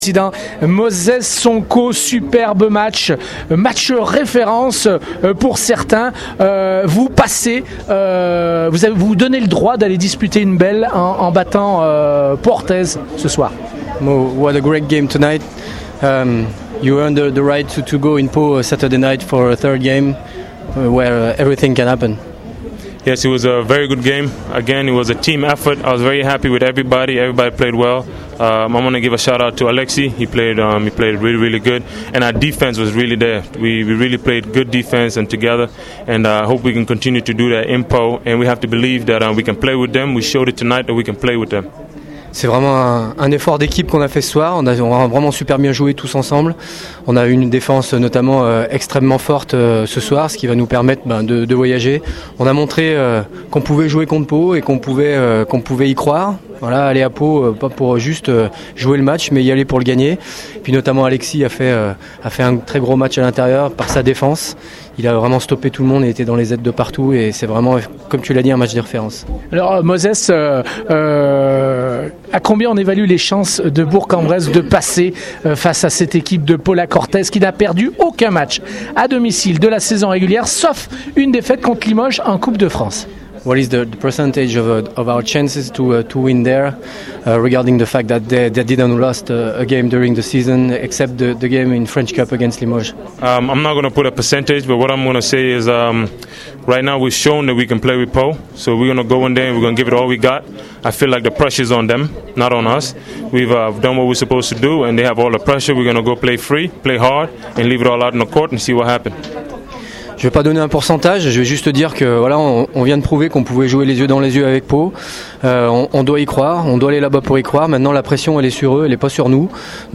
On écoute les réactions d’après-match